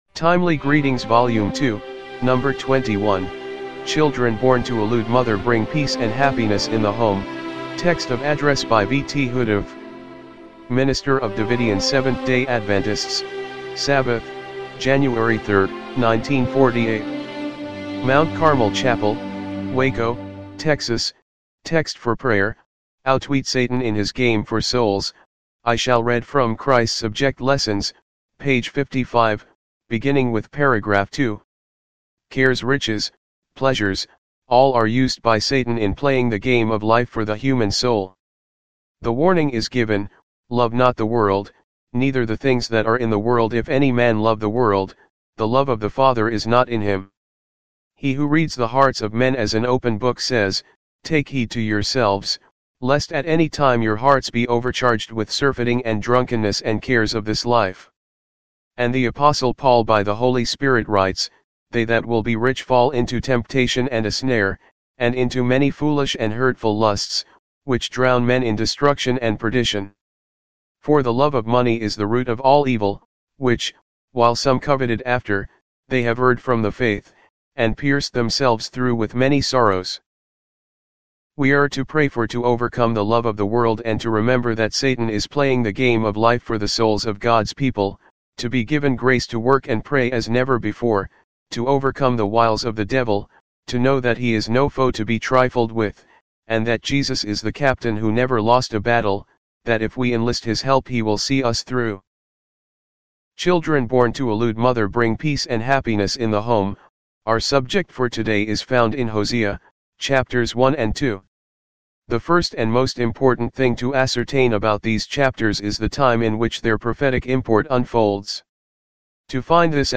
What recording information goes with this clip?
MINISTER OF DAVIDIAN 7TH-DAY ADVENTISTS SABBATH, JANUARY 3, 1948 MT. CARMEL CHAPEL WACO, TEXAS